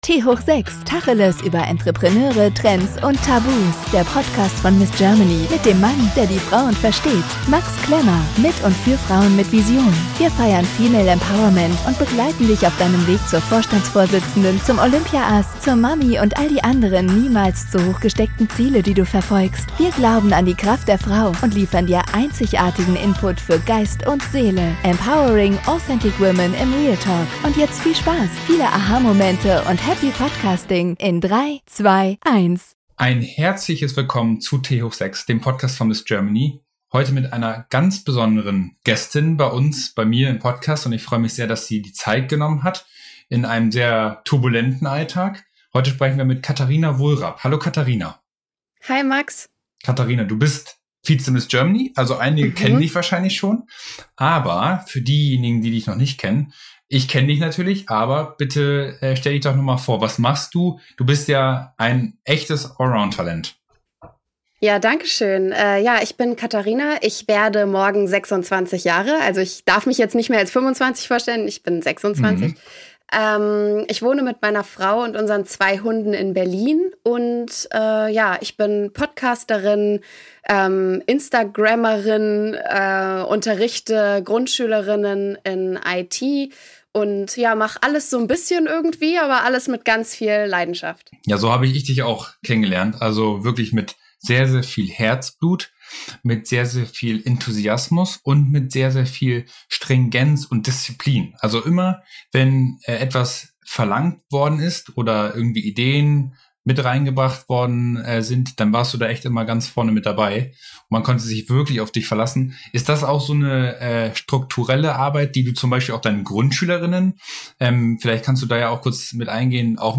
Wir sprechen im Tanga-Talk mit interessanten Frauen über Trends, aber genauso auch über Tabus.